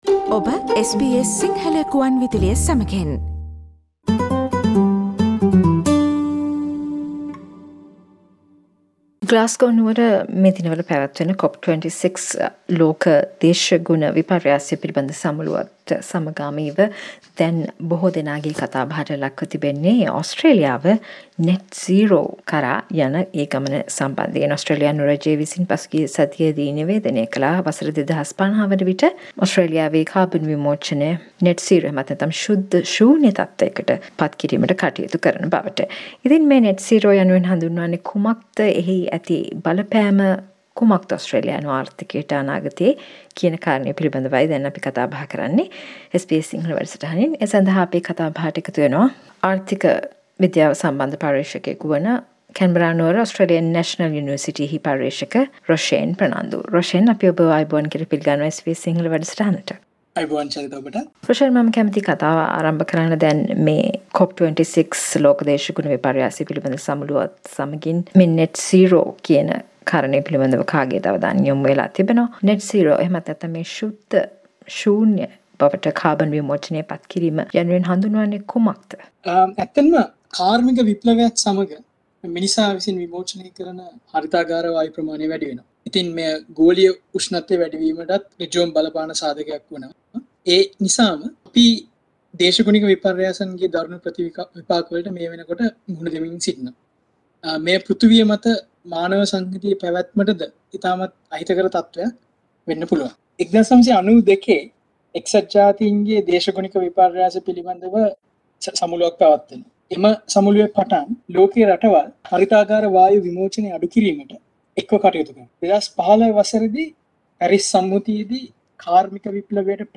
A discussion about economic impact of Net Zero emissions by 2050, as committed by Australia.